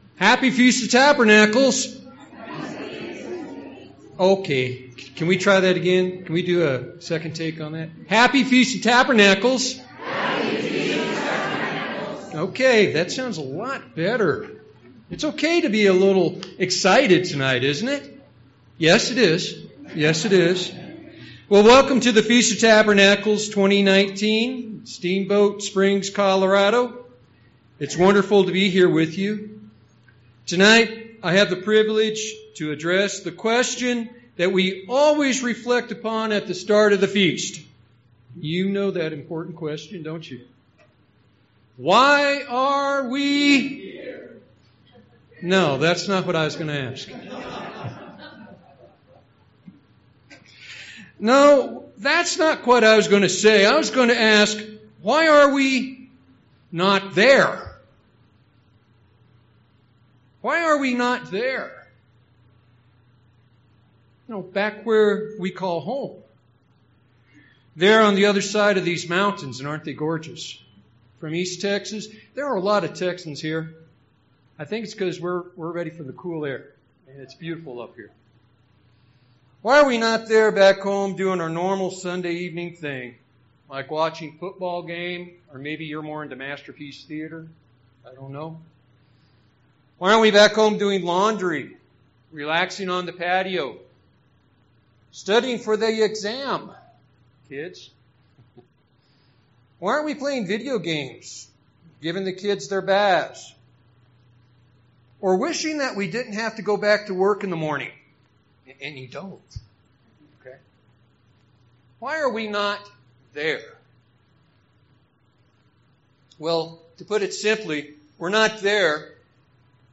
This sermon was given at the Steamboat Springs, Colorado 2019 Feast site.